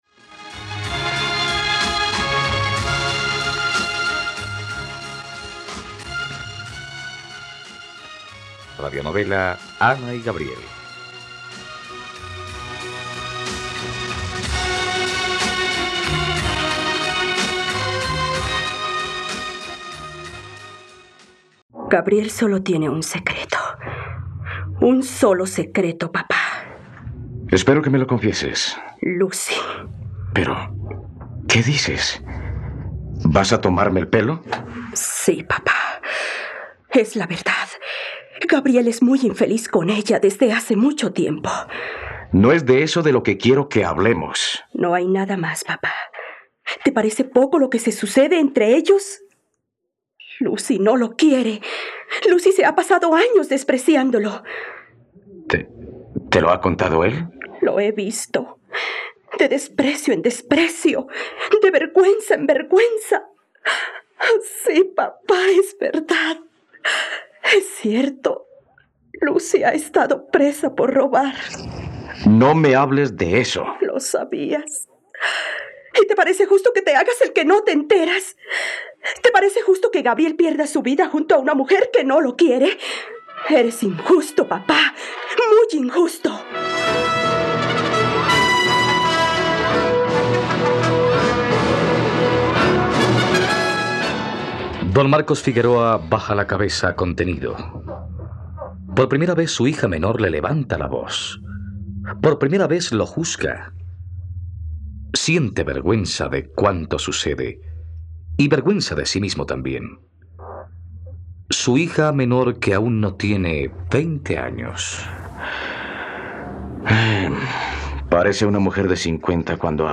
..Radionovela. Escucha ahora el capítulo 68 de la historia de amor de Ana y Gabriel en la plataforma de streaming de los colombianos: RTVCPlay.